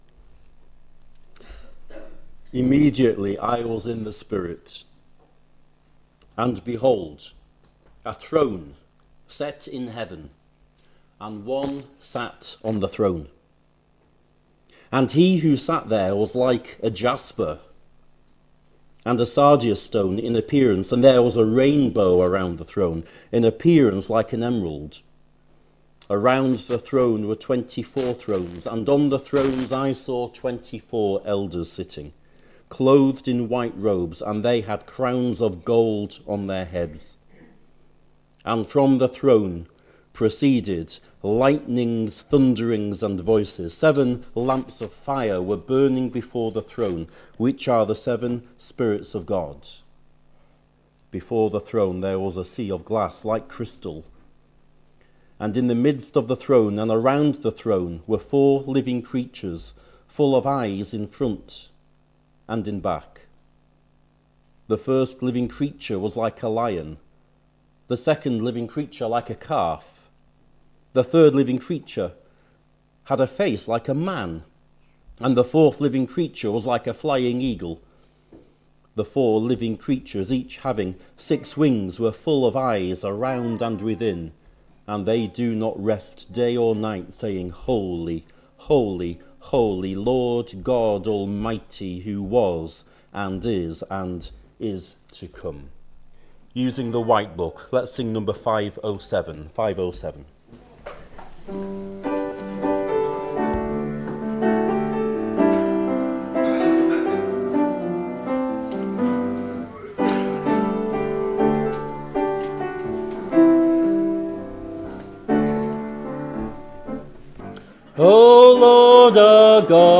Below is audio of the full service.
2026-03-22 Morning Worship If you listen to the whole service on here (as opposed to just the sermon), would you let us know?